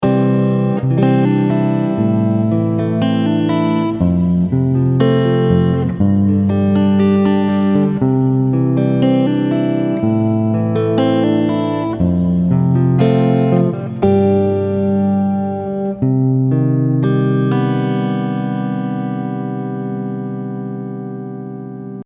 Here is another example with an open C minor major 7 ending.
C, Am, F, G, CmMaj7
Somehow it’s finished, but somehow it’s not.